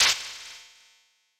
Super Smash Bros. game sound effects
Fox - Shield Deflect.wav